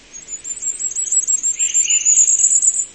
Fiorrancino
Regulus ignicapillus
Richiamo ‘zit’ o ‘ziit’, grave in modo percettibile, meno sottile e meno flebile di quello del Regolo; spesso ne emette uno solo. Canto una singola nota ripetuta, terminante in un trillo.
Fioraccino_Regulus_ignicapillus.mp3